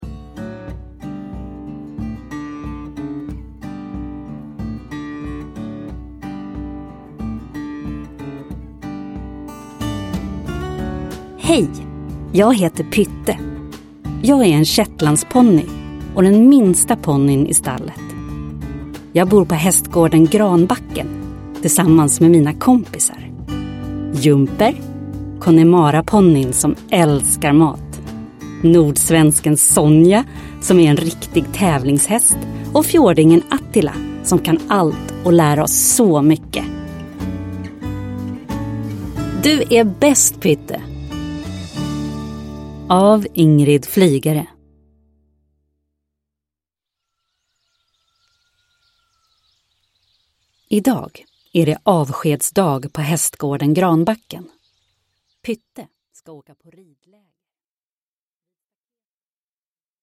Du är bäst, Pytte! – Ljudbok – Laddas ner